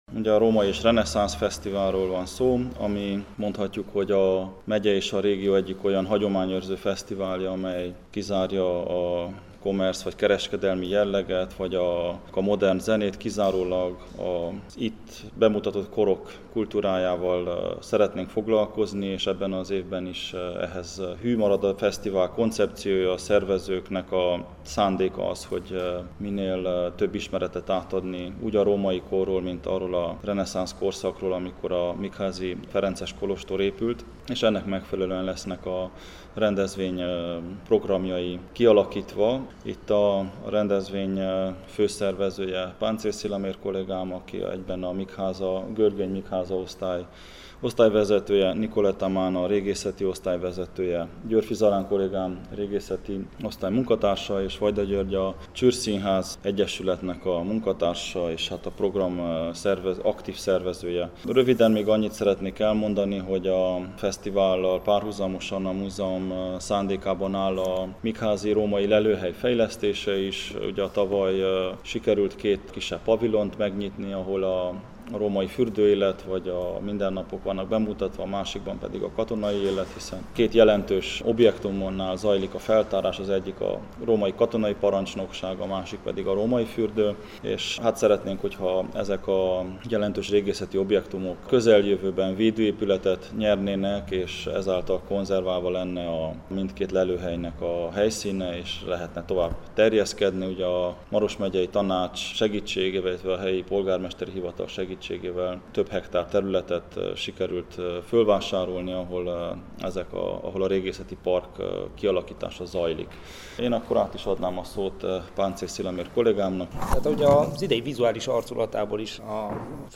A főszervező Maros Megyei Múzeum munkatársai tájékoztatnak